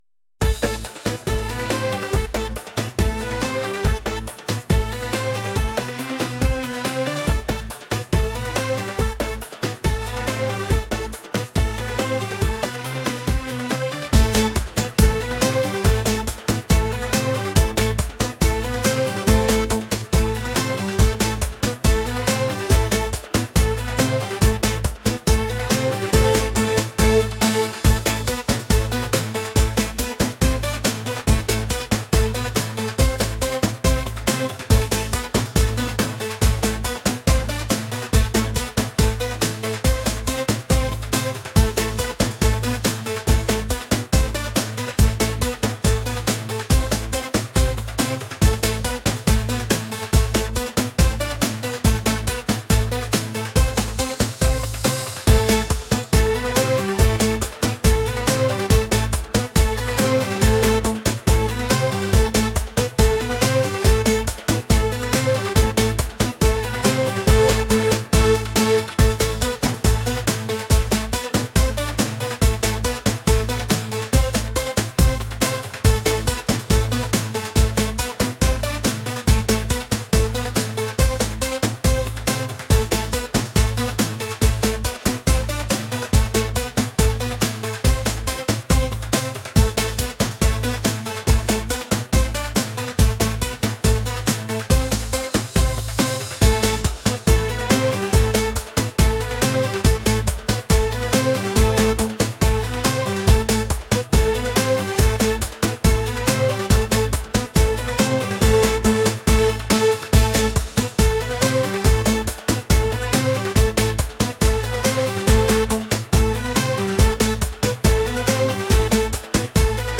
energetic | catchy | pop